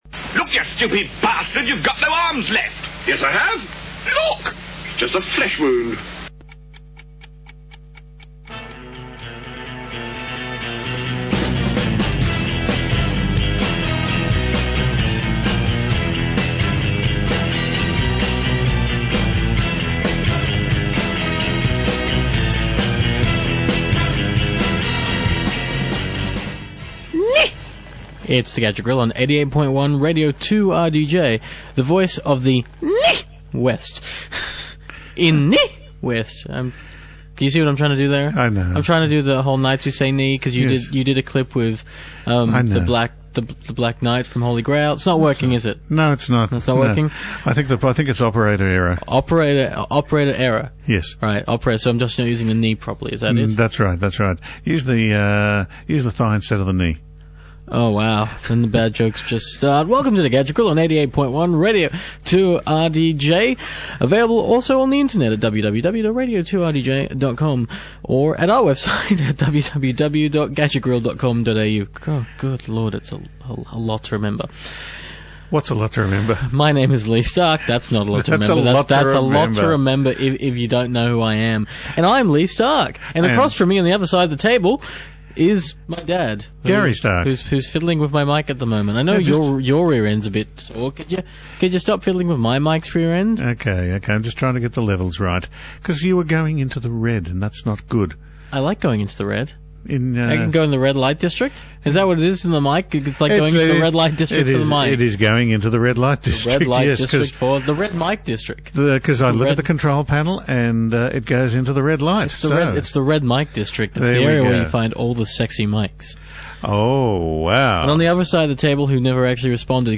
Plus there’s news, music , and two gibbering idiots occasionally yelling at each other.